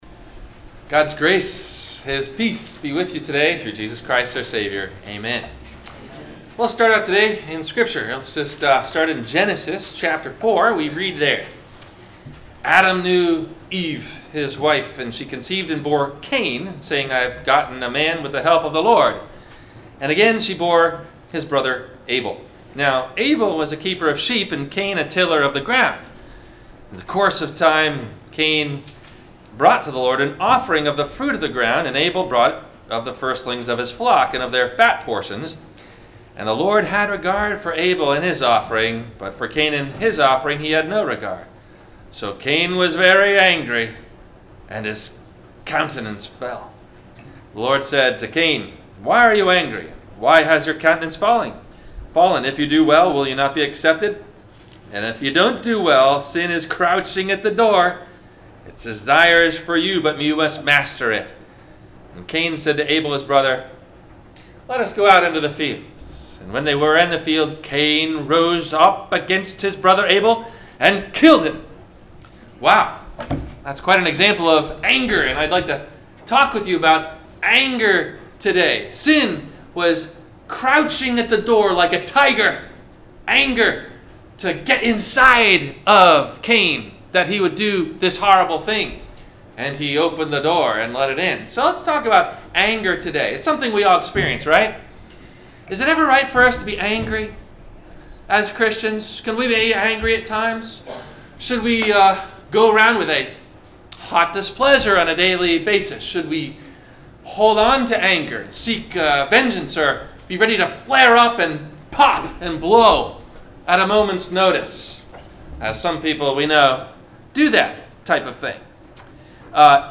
- Sermon - September 23 2018 - Christ Lutheran Cape Canaveral